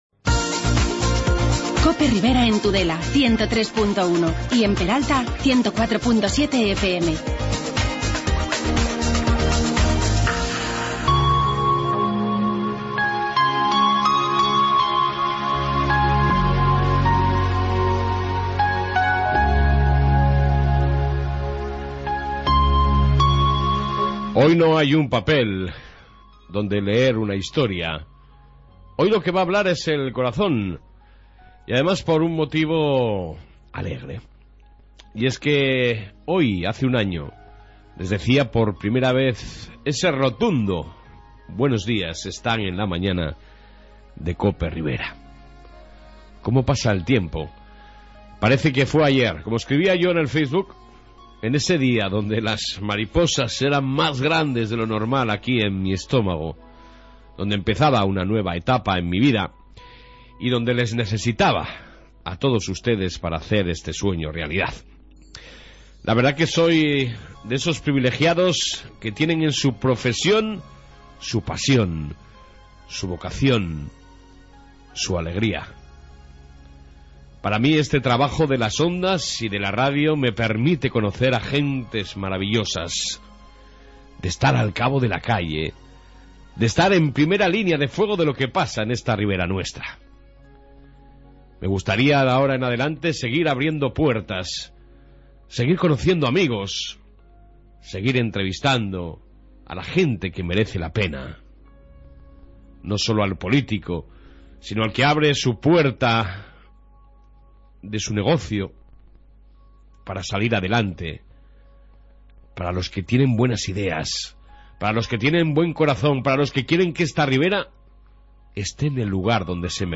AUDIO: Reflexión primer aniversario, entrevista pasión de milagro y Entrevista Semana santa Corella